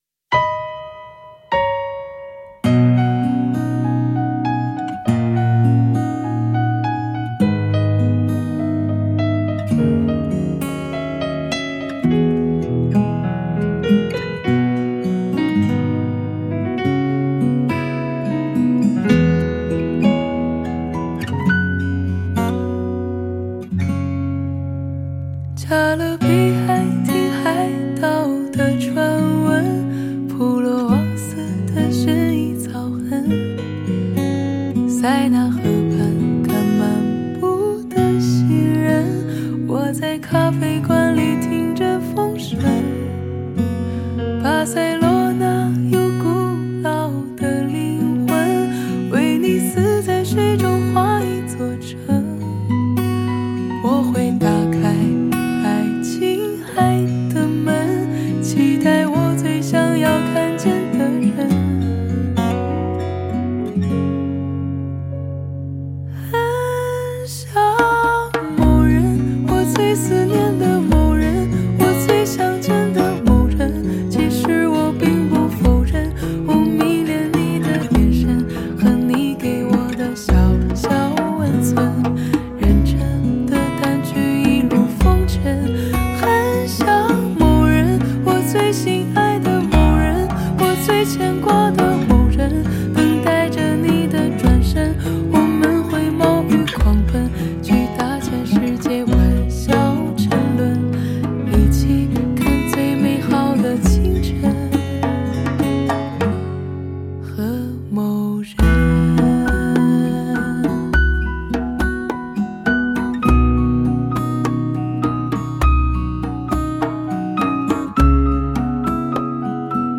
128K低品质试听